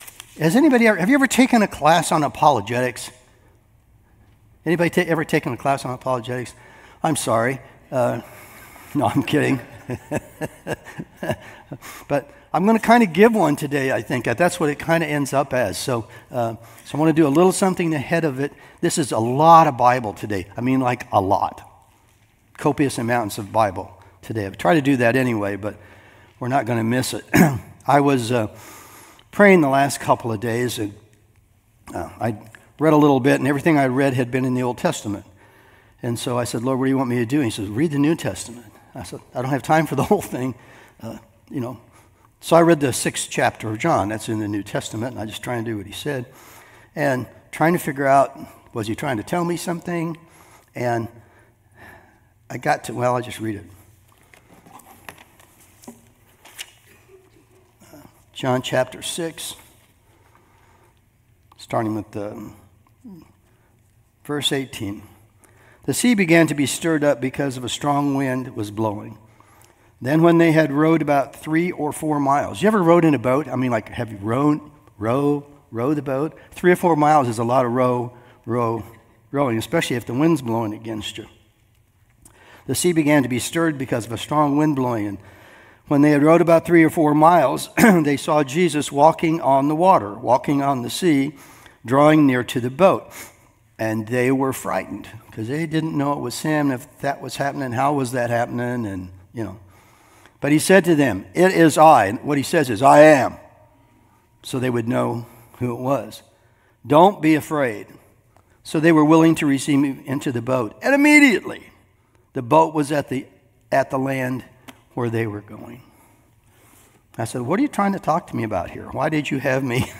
Service Type: Sunday Morning Sermon